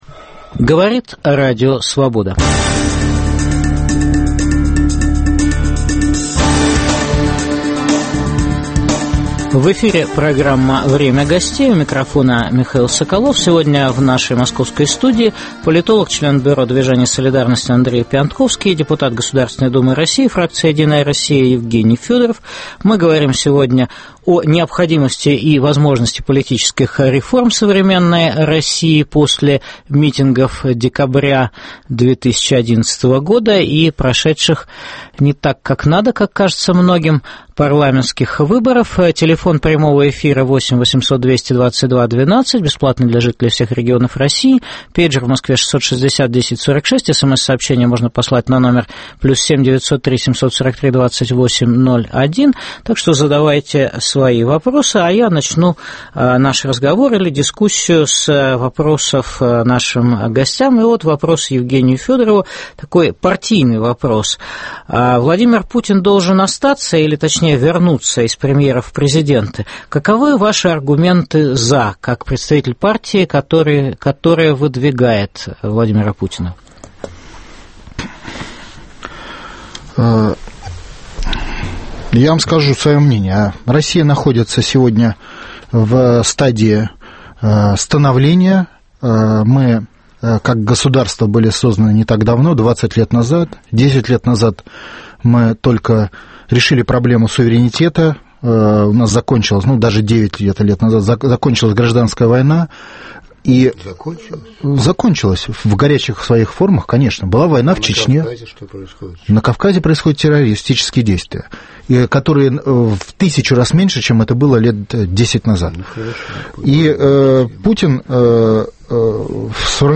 Может ли Владимир Путин, отложив политические реформы, довести Россию до новой революции? В программе дискутируют: публицист и политолог, член бюро движения "Солидарность" Андрей Пионтковский и депутат Государственной Думы России ( фракция "Единая Россия" ) Евгений Федоров.